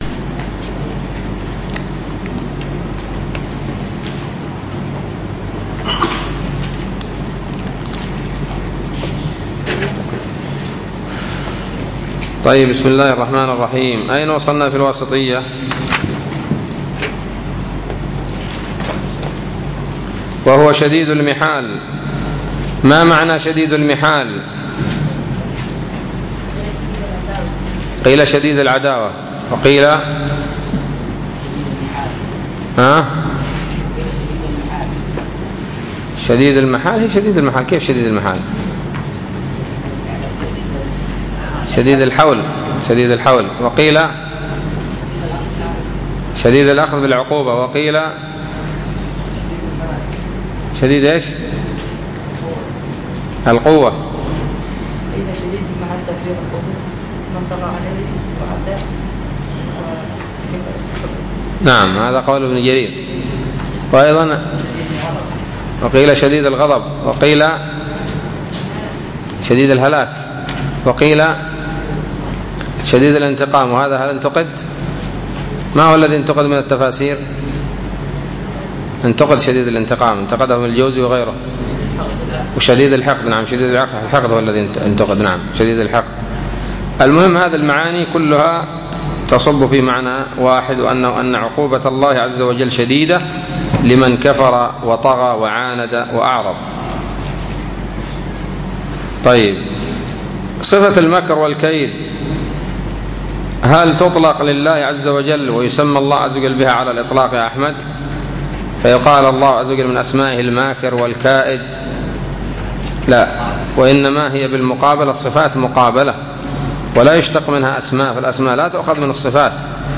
الدرس السادس والستون من شرح العقيدة الواسطية